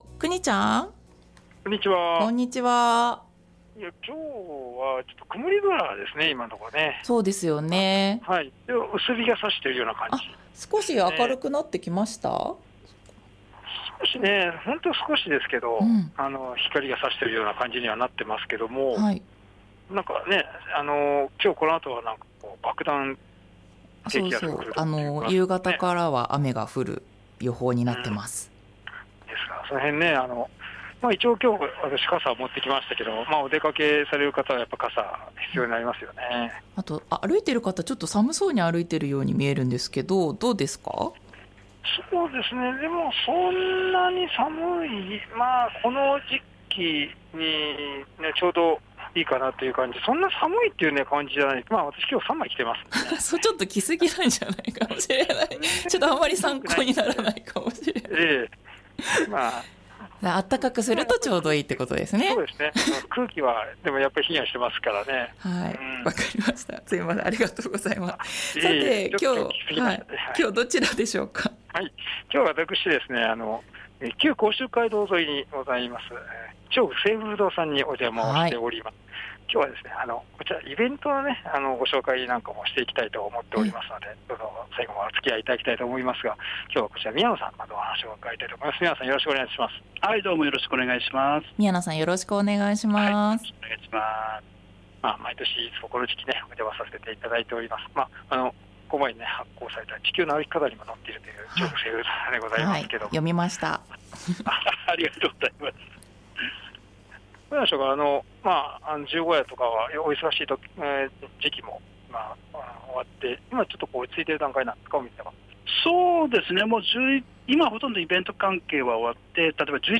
今日は日本茶の日だそうです そんな日本茶にもあうお店からお届けしました 本日は旧甲州街道ぞいにあります 千代富清風堂さんにお邪魔しました。